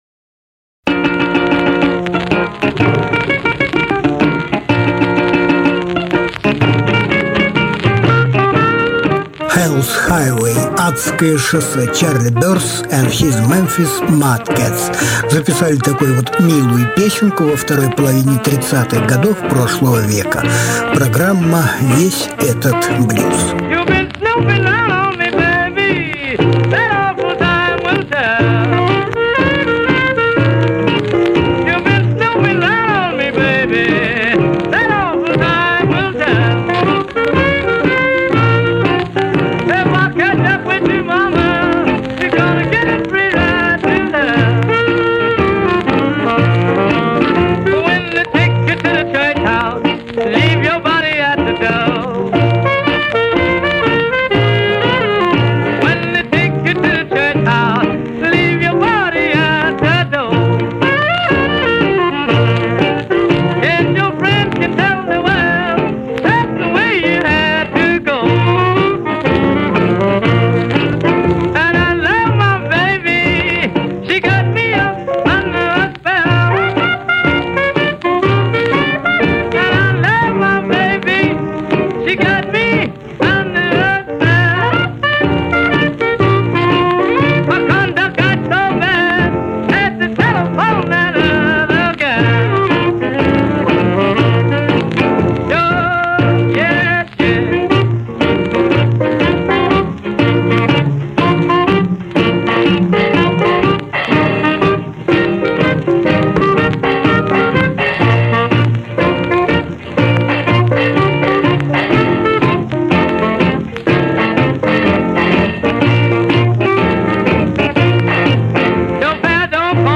Жанр: Блюзы и блюзики